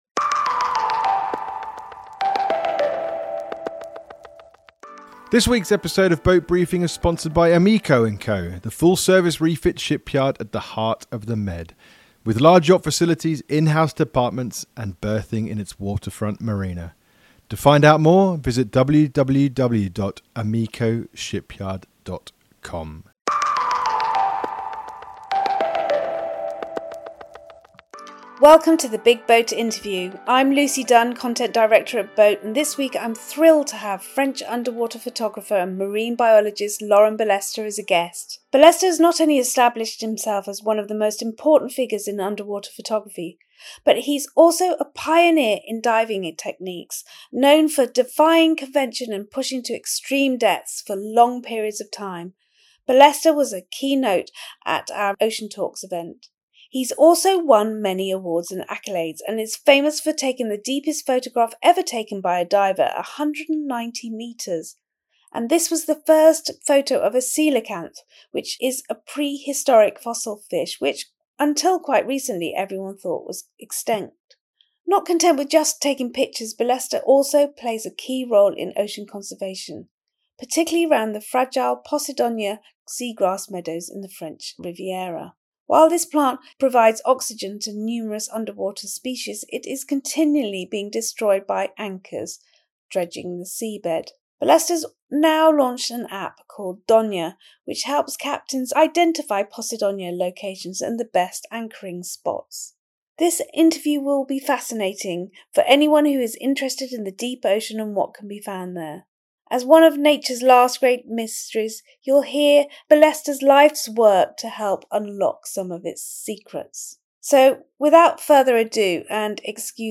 The Big BOAT Interview: photographer Laurent Ballesta on diving deep, discovering ‘dinosaur’ fish and his new app, DONIA